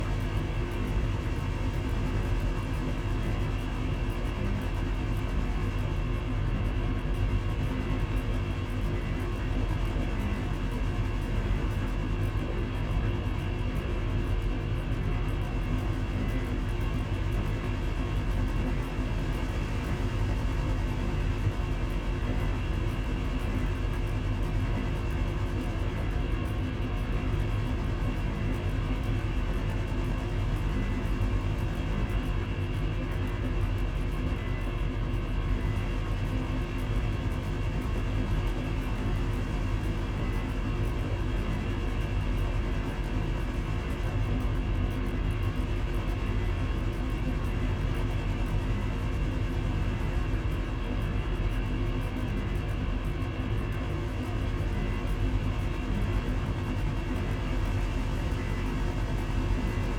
Machine Room Loop 4.wav